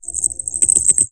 braceletchime.ogg